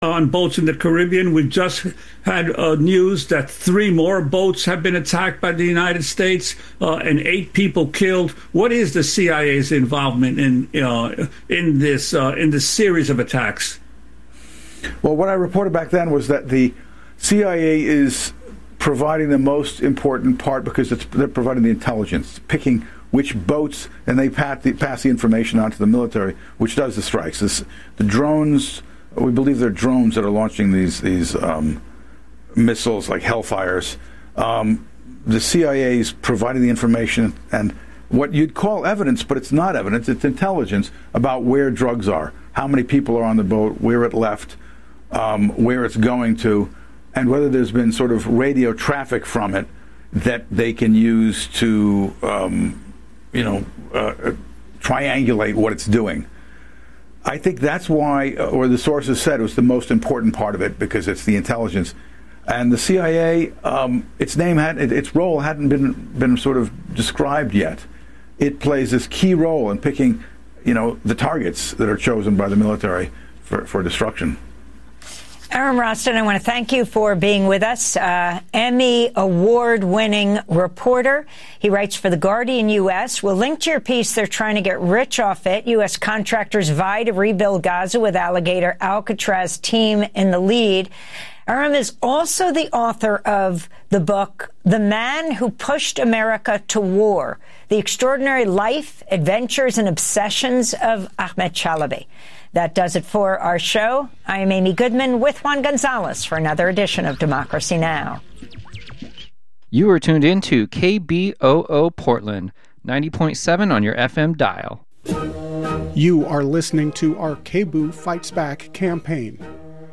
Evening News on 12/16/25